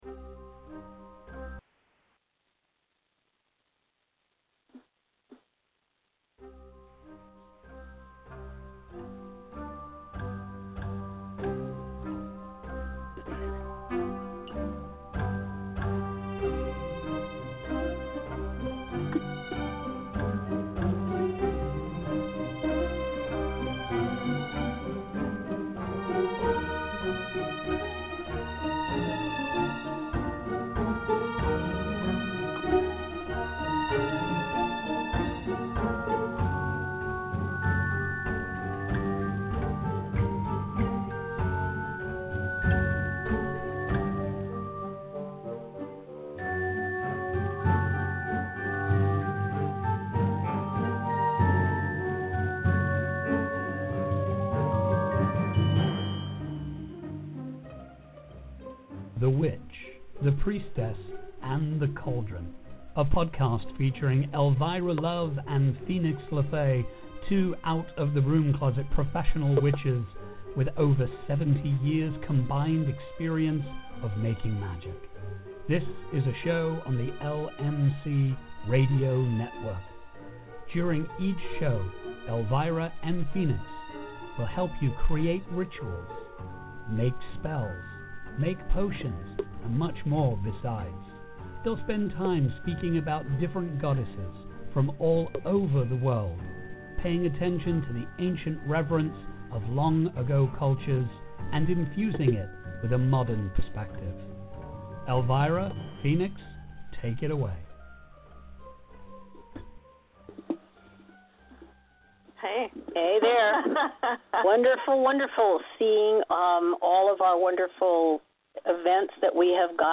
with hosts